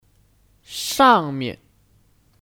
上面 Shàngmian : Diatas(Alternatif dari 上面 Shàngbian)